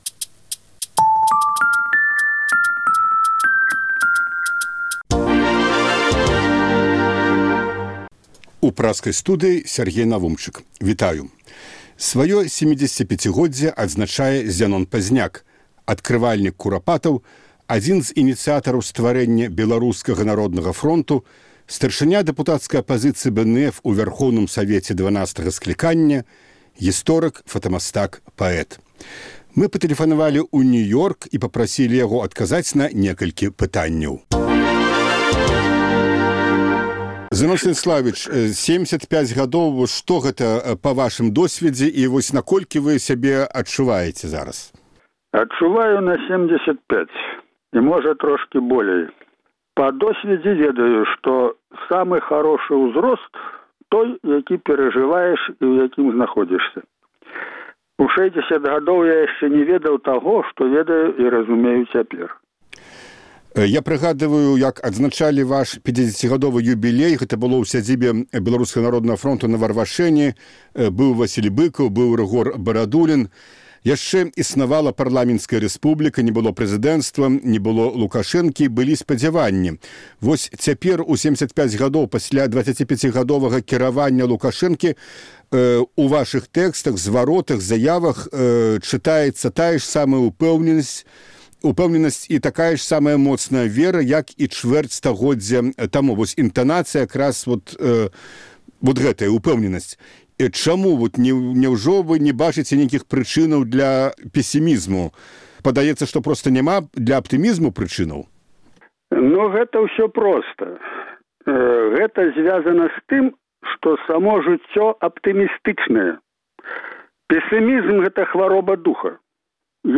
Сваё 75-годзьдзе адзначае Зянон Пазьняк — адкрывальнік Курапатаў, адзін з ініцыятараў стварэньня Беларускага Народнага Фронту, старшыня дэпутацкай Апазыцыі БНФ у Вярхоўным Савеце 12-га скліканьня, фотамастак, паэт, гісторык. Зь Зянонам Пазьняком, які цяпер знаходзіцца ў Нью-Ёрку, гутарыць Сяргей Навумчык.